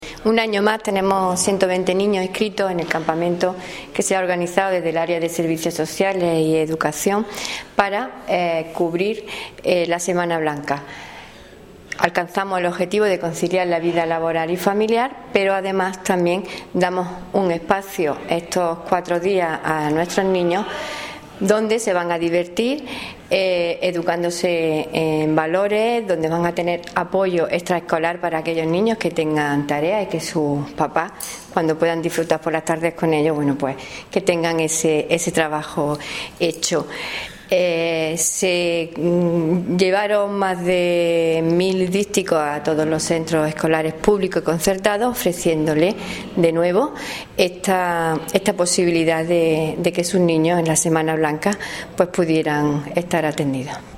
Cortes de voz
Audio: concejala de Derechos Sociales   843.7 kb  Formato:  mp3